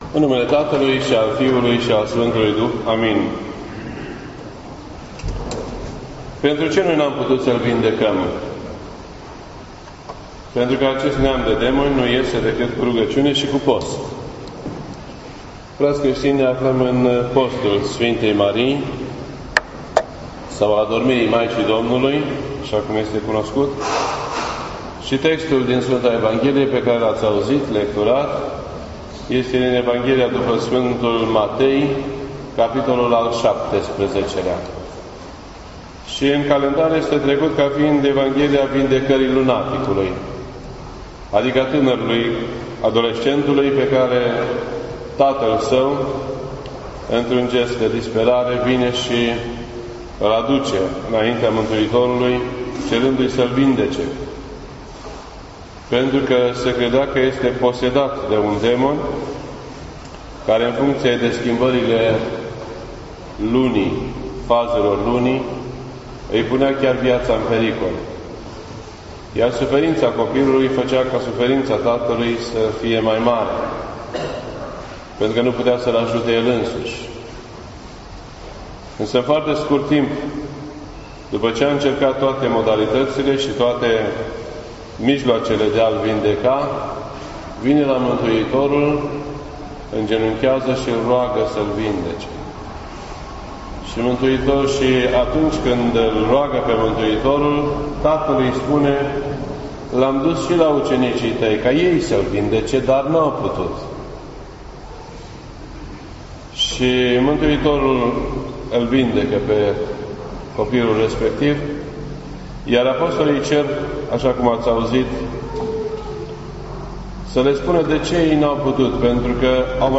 This entry was posted on Sunday, August 13th, 2017 at 7:04 PM and is filed under Predici ortodoxe in format audio.